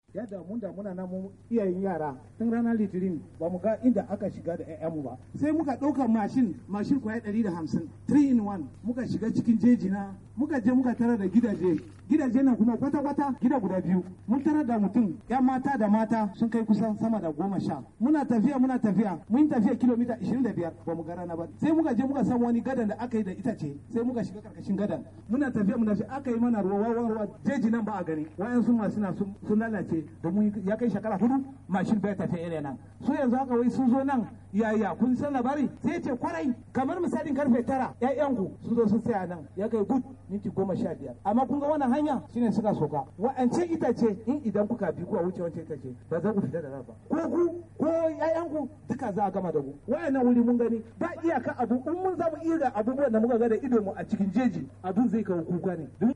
A lokacin da yake jawabi a gaban gwamna Kashim Shettima na Jihar Borno da Sanata Mohammed Ali Ndume mai wakiltar Borno ta Kudu, mahaifin wata dalibar da aka sace daga makarantar sakabdare ta Chibok yace a lokacin da suka ji labari, sun garzaya makarantar domin su san abinda ke faruwa.